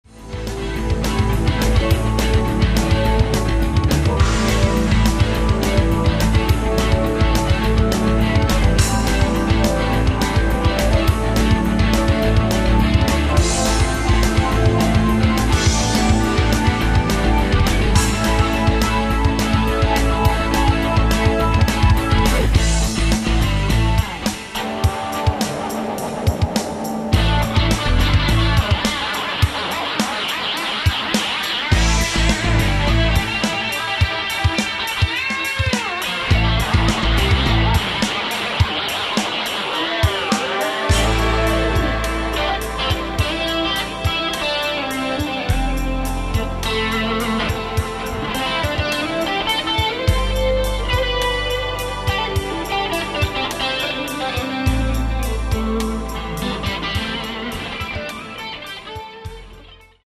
ein reines Rock-Album